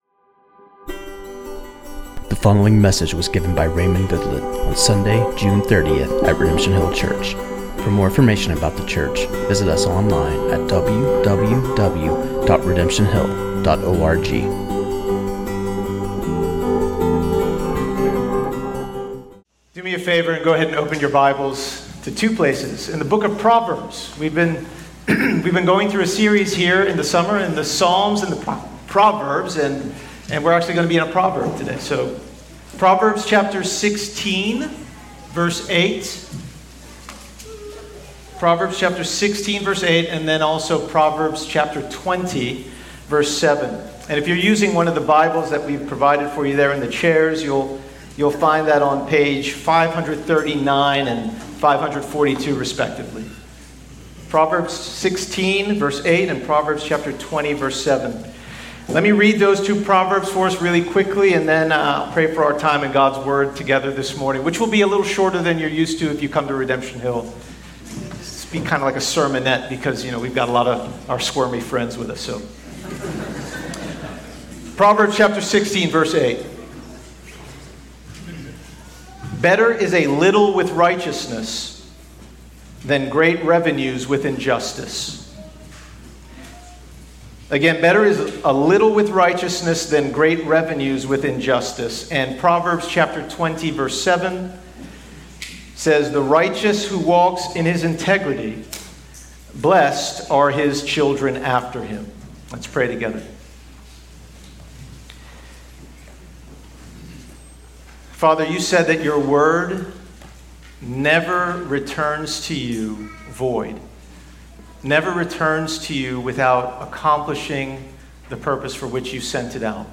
This sermon on Proverbs 16:8
at Redemption Hill Church on Sunday